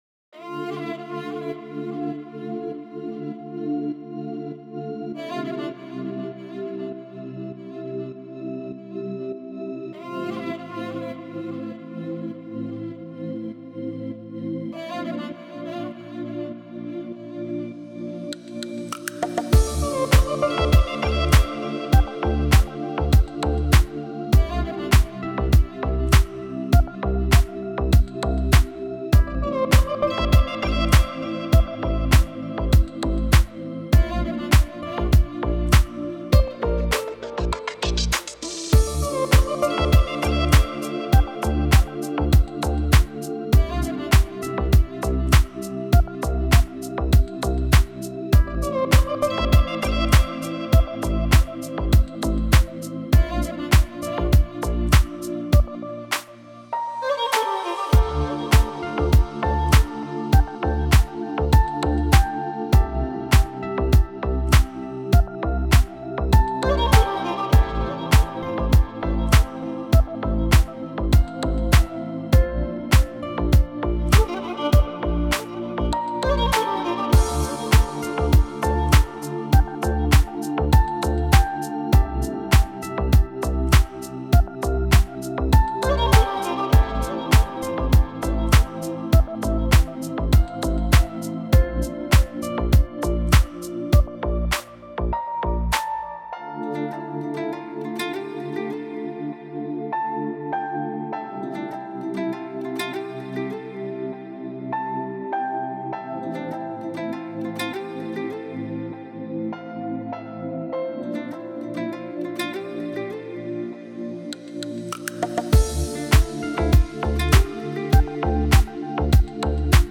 موسیقی کنار تو
دیپ هاوس ریتمیک آرام موسیقی بی کلام